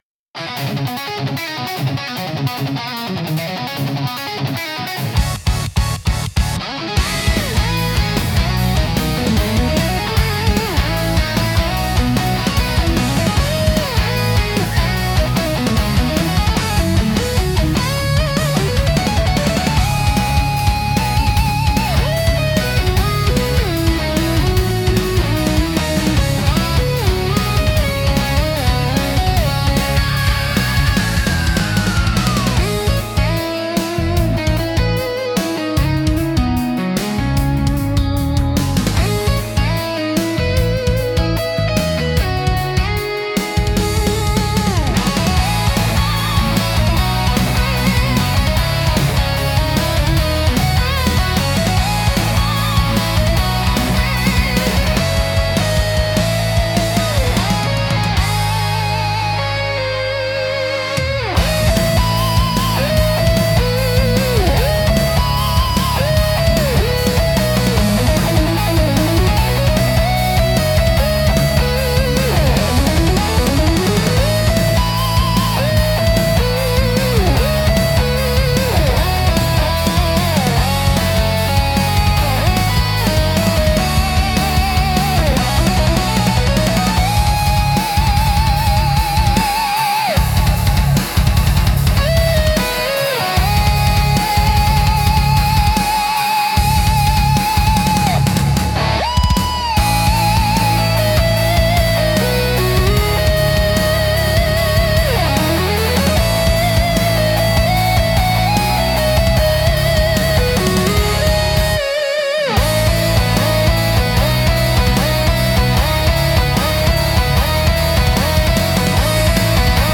BGMセミオーダーシステムドライブは、クールで疾走感あふれるロック系のオリジナルジャンルです。